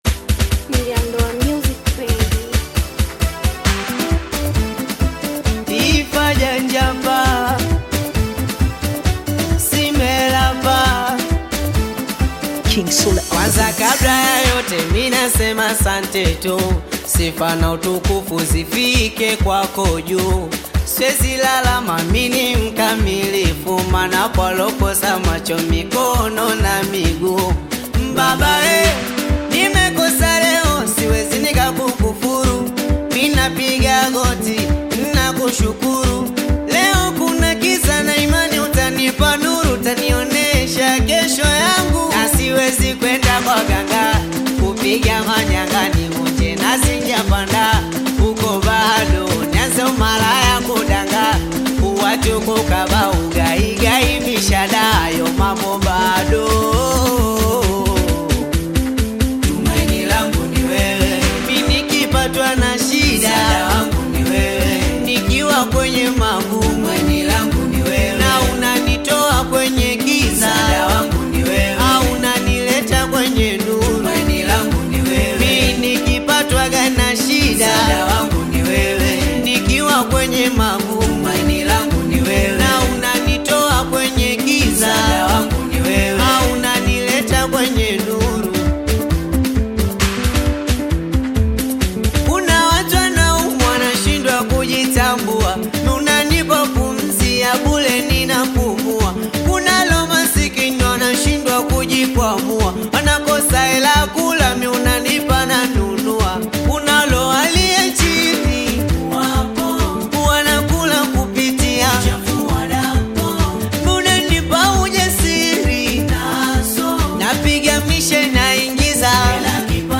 Singeli music track
Tanzanian Bongo Flava artist and singer
Singeli song